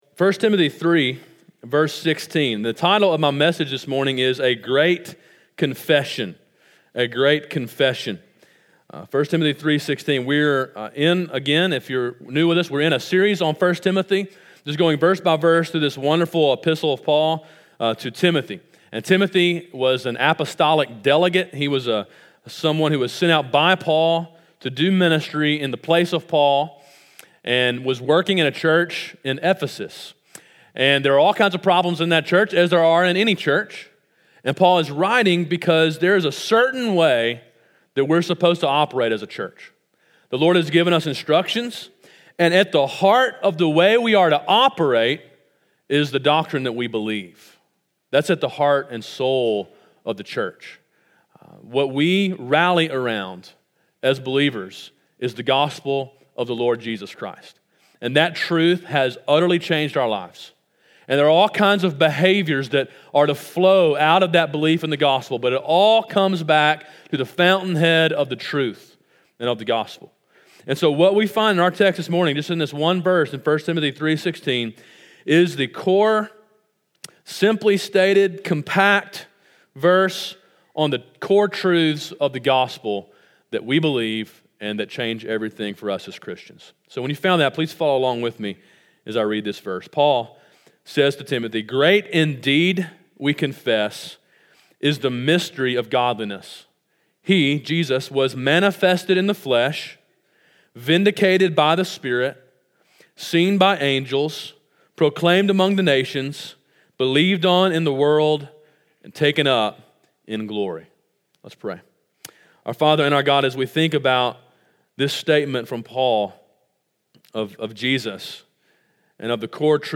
Sermon: “A Great Confession” (1 Timothy 3:16) – Calvary Baptist Church